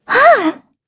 Xerah-Vox-Laugh_kr.wav